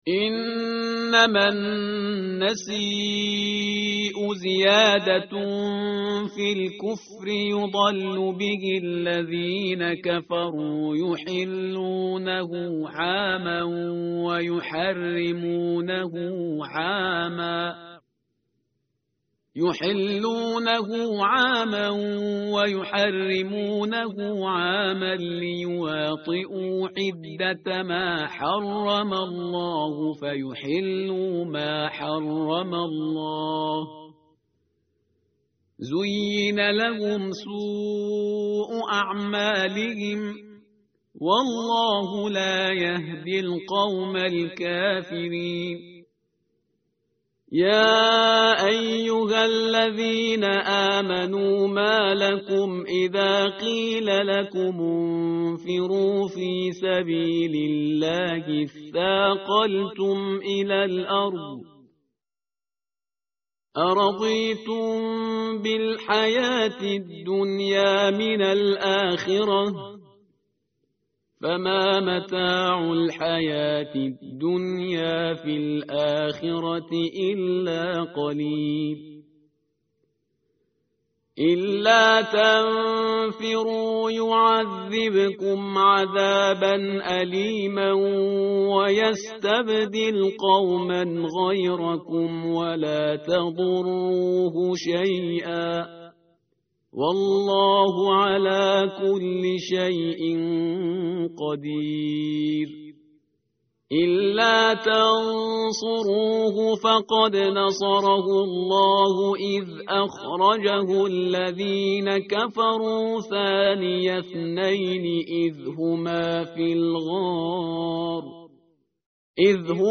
متن قرآن همراه باتلاوت قرآن و ترجمه
tartil_parhizgar_page_193.mp3